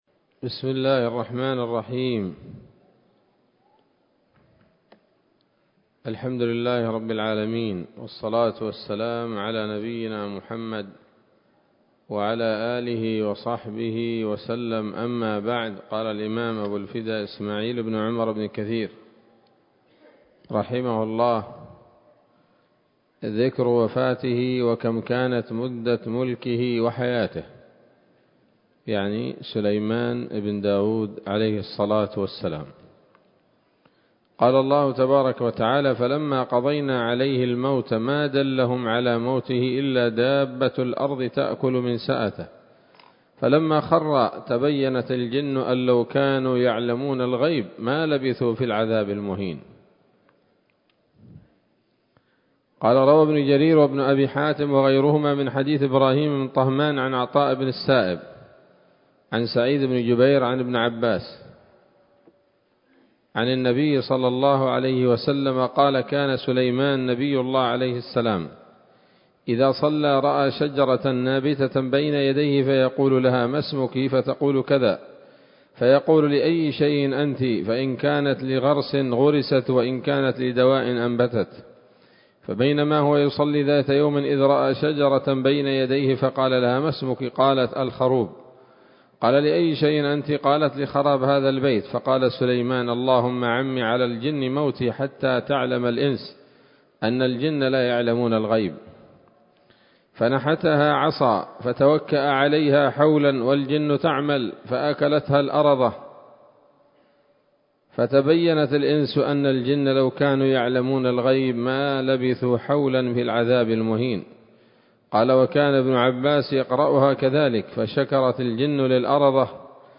‌‌الدرس السادس والعشرون بعد المائة من قصص الأنبياء لابن كثير رحمه الله تعالى